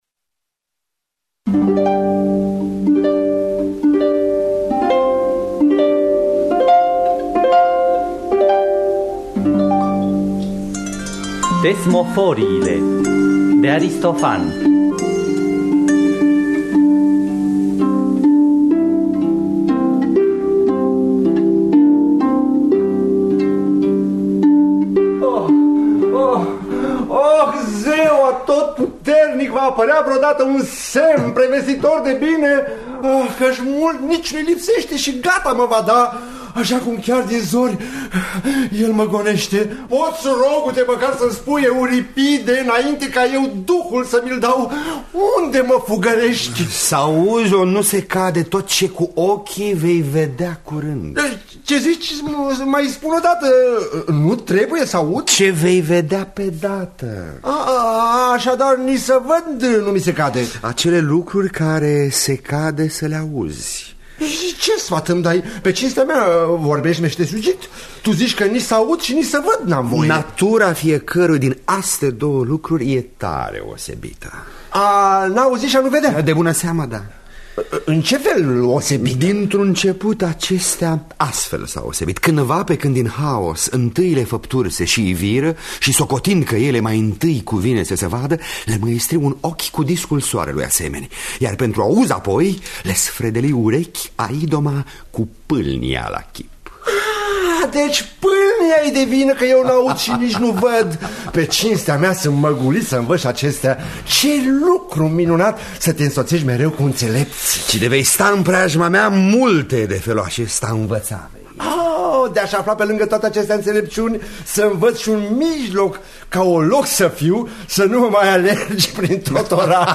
Thesmophoriile de Aristofan – Teatru Radiofonic Online
Traducerea şi adaptarea radiofonică